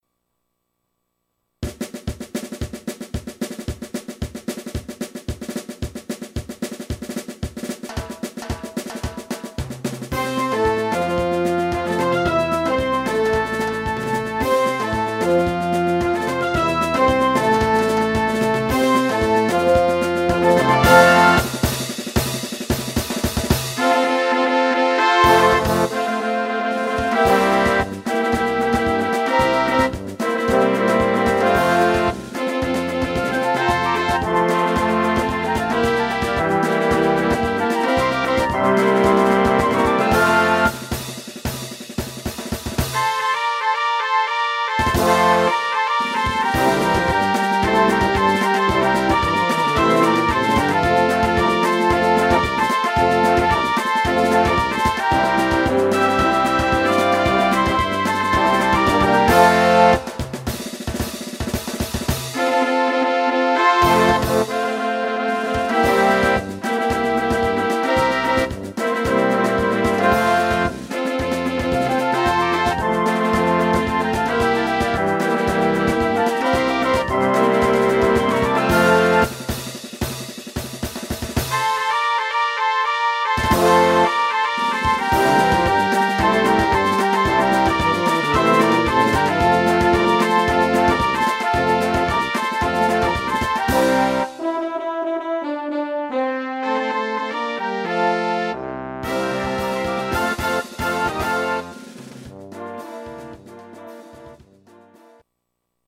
een aanstekelijke medley
Marchingband  –  pdf   –